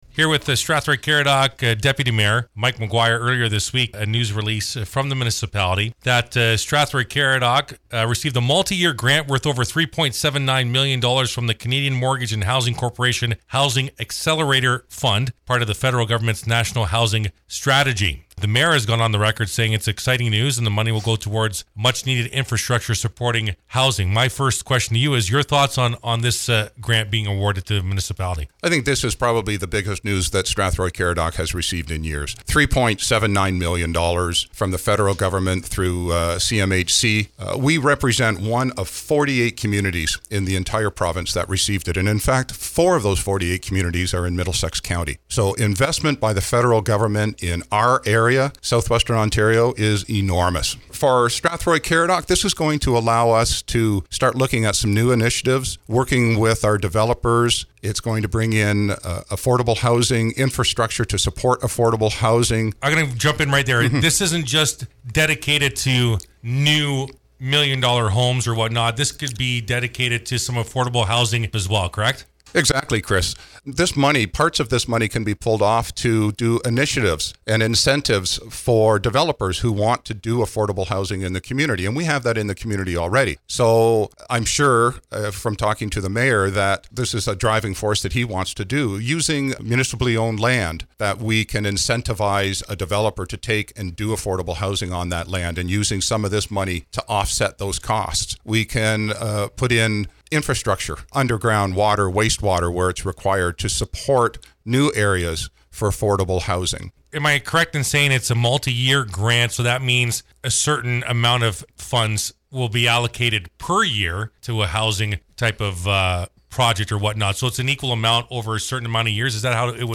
We sat down for an exclusive interview with Deputy Mayor Mike McGuire to get his take on the announcement and housing within the municipality in general.